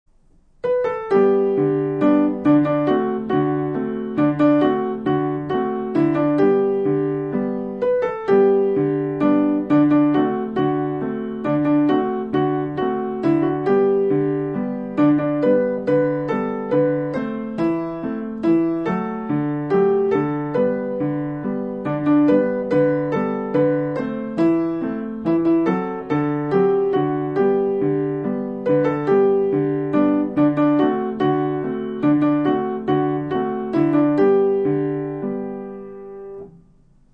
ピアノ演奏